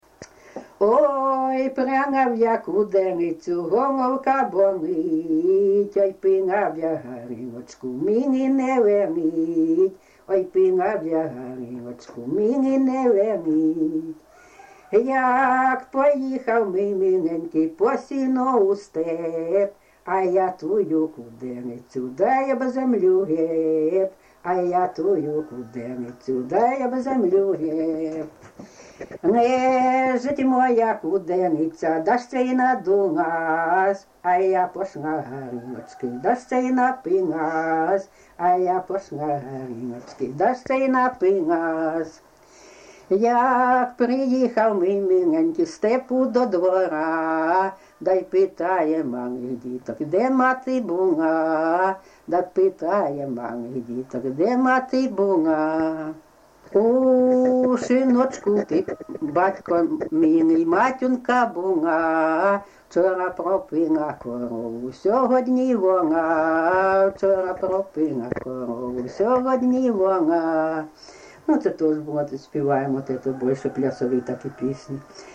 ЖанрПісні з особистого та родинного життя, Жартівливі
Місце записус. Курахівка, Покровський район, Донецька обл., Україна, Слобожанщина